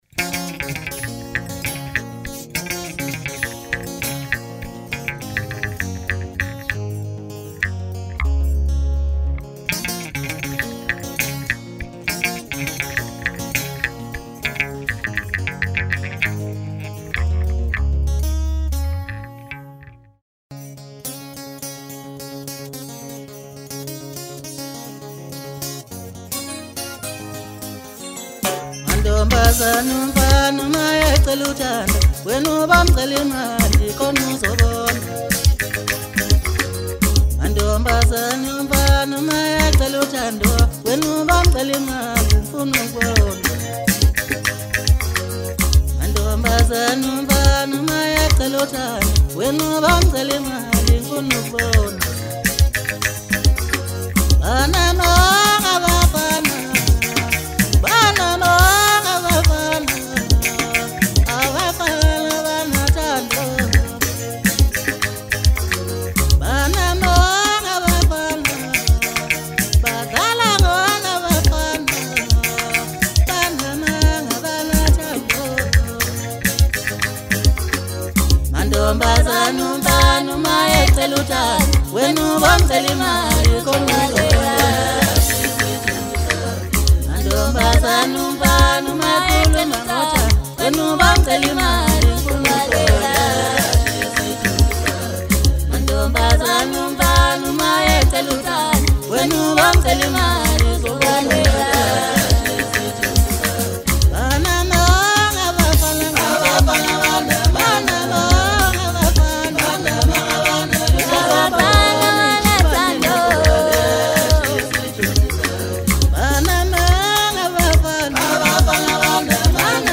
Home » Maskandi » Maskandi Music
Maskandi Songs